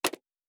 pgs/Assets/Audio/Sci-Fi Sounds/Mechanical/Device Toggle 02.wav at 7452e70b8c5ad2f7daae623e1a952eb18c9caab4
Device Toggle 02.wav